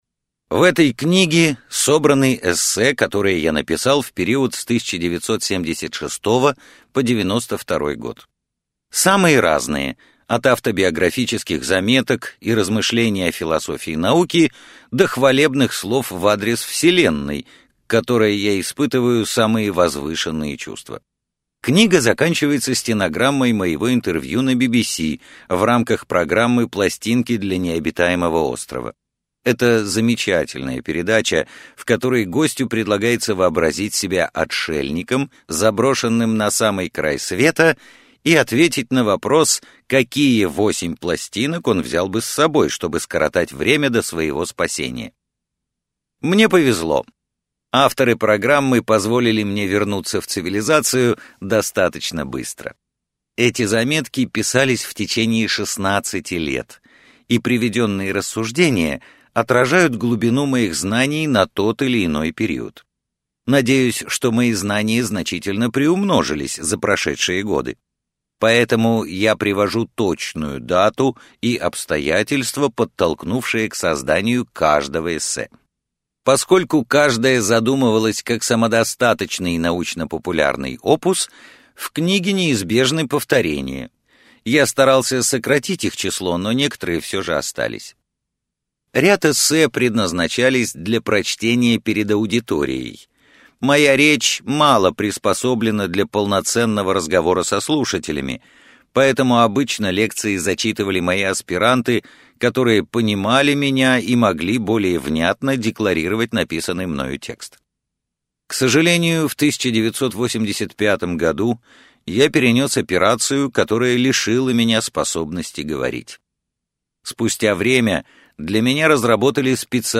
Аудиокнига Черные дыры и молодые вселенные - купить, скачать и слушать онлайн | КнигоПоиск